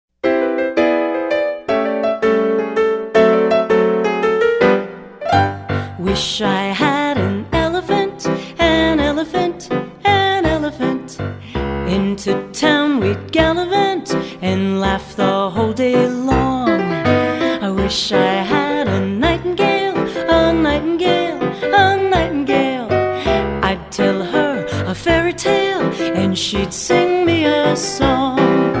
Vocal and
▪ The full vocal track.